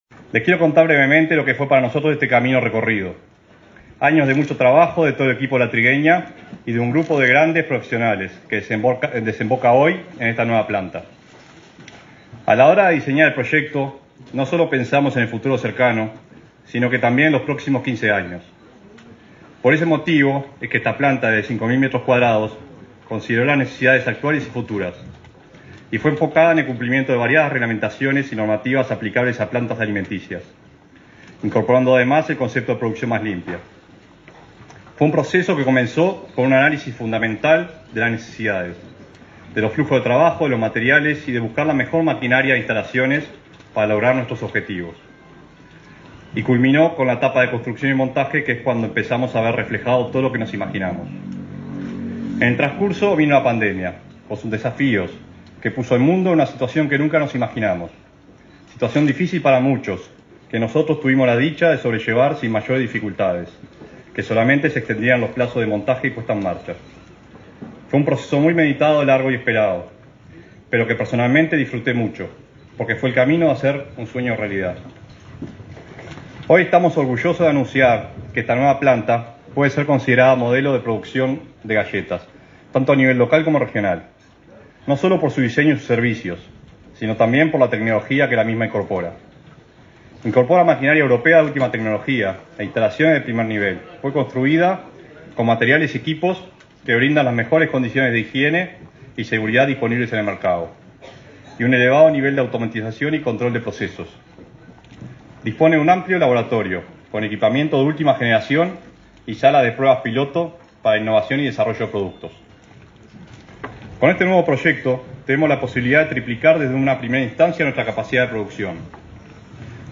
Palabras de autoridades en inauguración de planta de La Trigueña